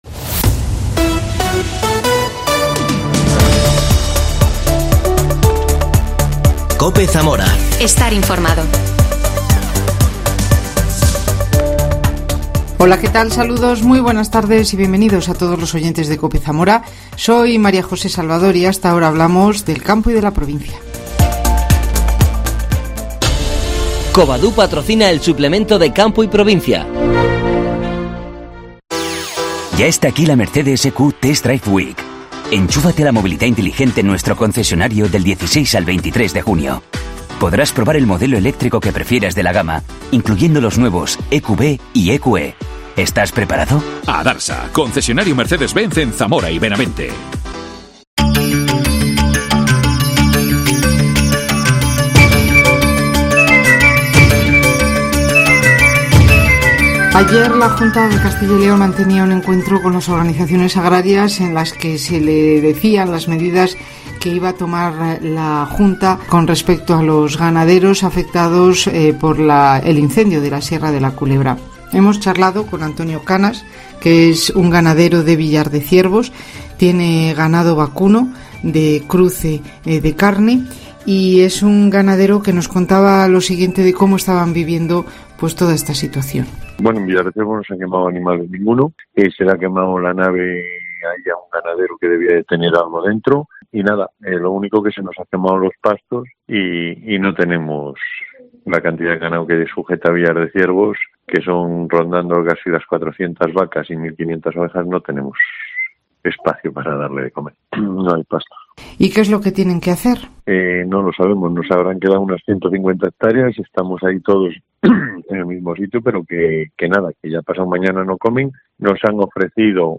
Entrevista a un ganadero de Villardeciervos